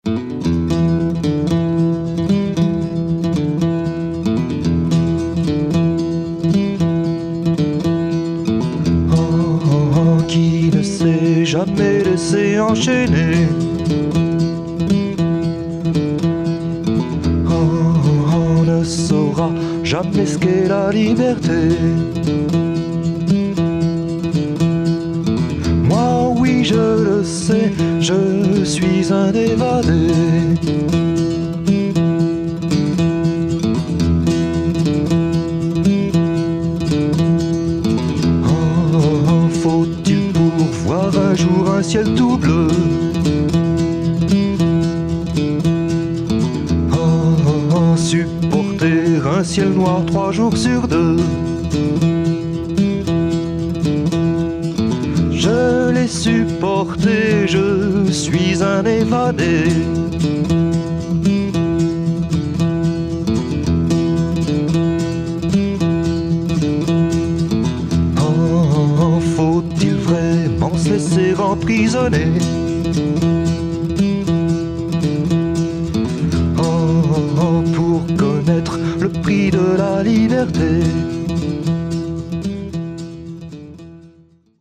a good jazz / pop / folk number